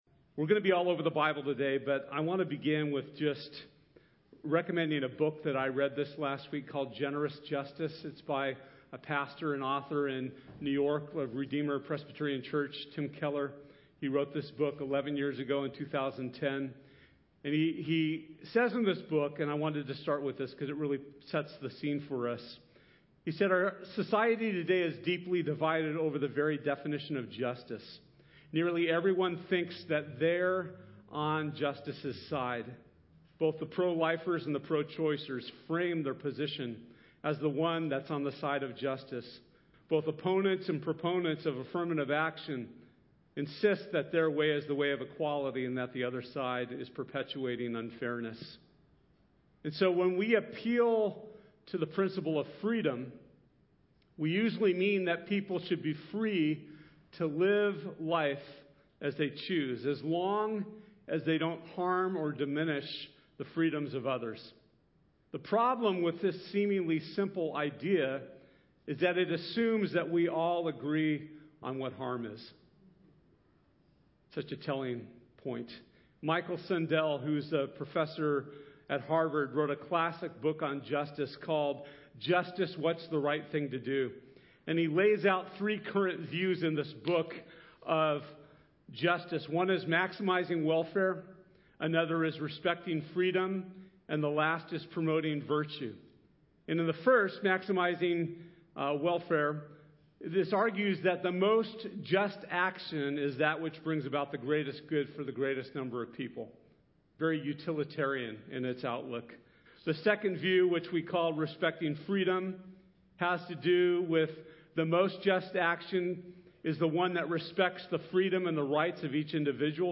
Free Indeed Service Type: Sunday This Sunday we’ll be talking about how we can find freedom from false concepts of justice.